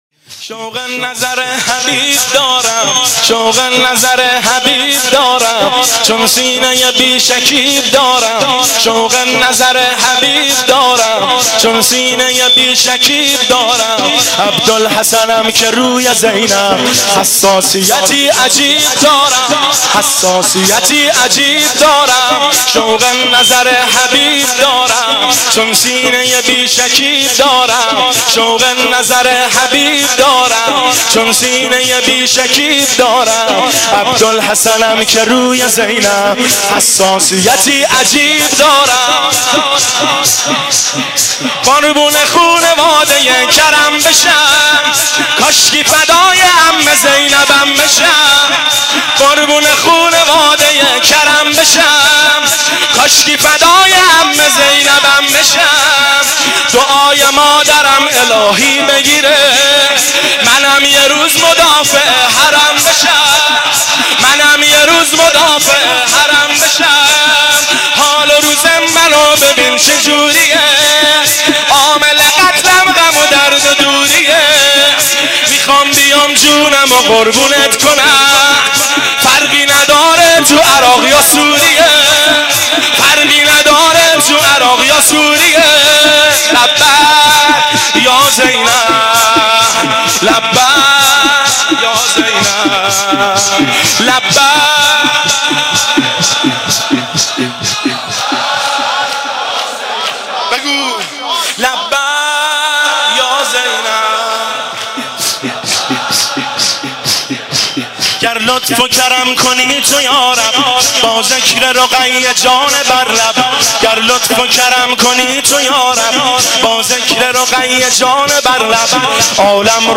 که در بین الحرمین تهران اجرا شده است
شور